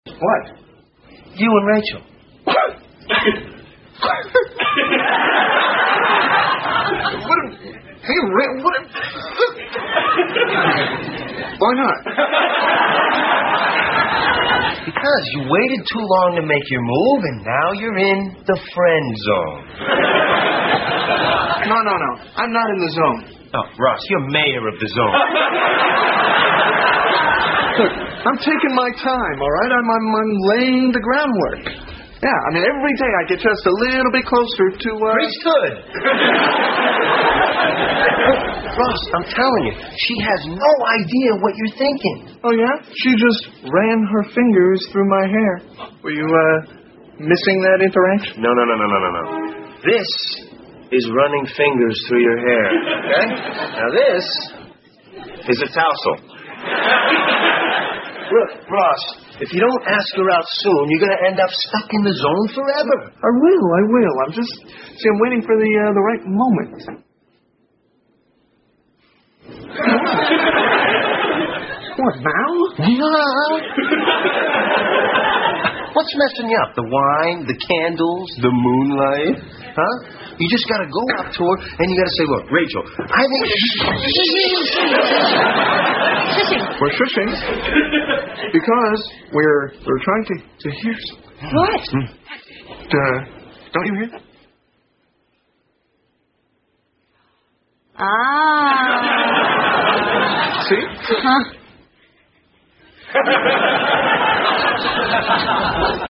在线英语听力室老友记精校版第1季 第79期:停电(6)的听力文件下载, 《老友记精校版》是美国乃至全世界最受欢迎的情景喜剧，一共拍摄了10季，以其幽默的对白和与现实生活的贴近吸引了无数的观众，精校版栏目搭配高音质音频与同步双语字幕，是练习提升英语听力水平，积累英语知识的好帮手。